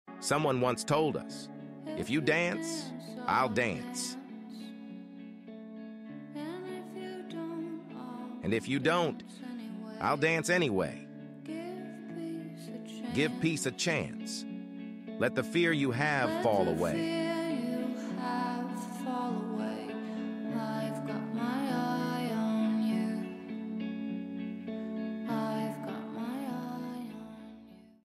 a romantic and melancholic song